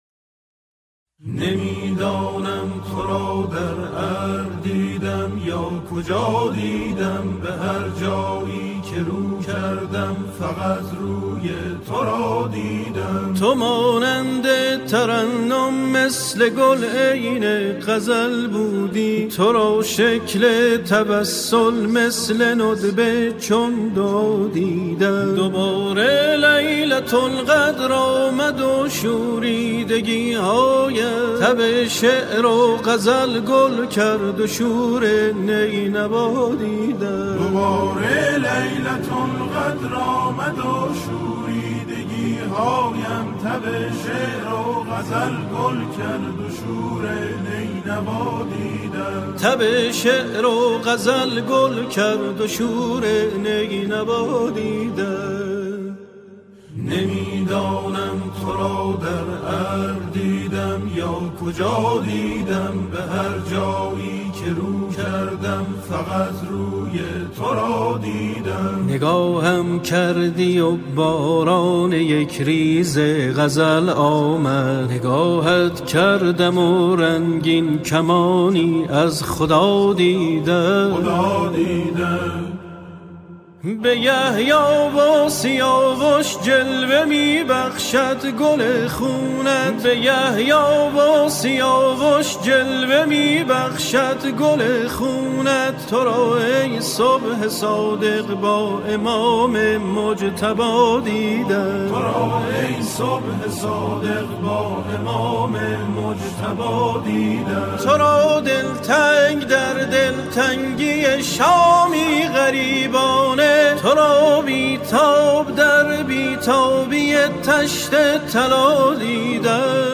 سرودهای امام حسین علیه السلام